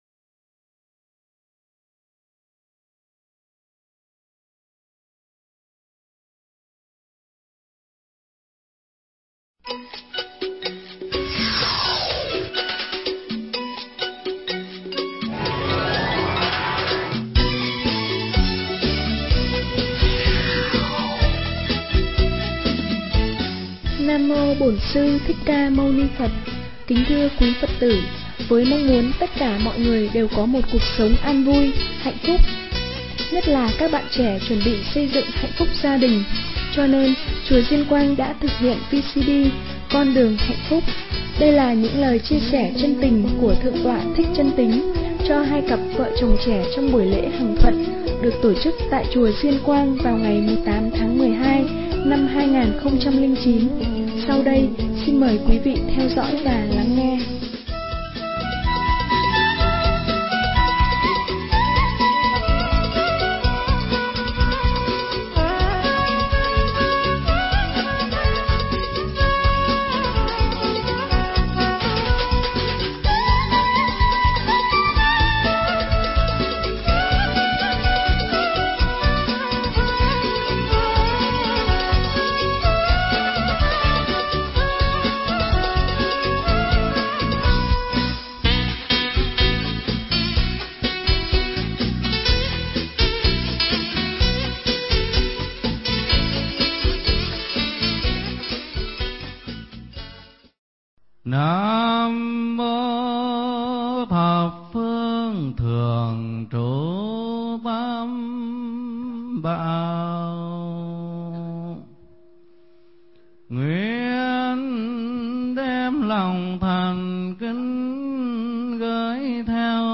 Nghe Mp3 thuyết pháp Con Đường Hạnh Phúc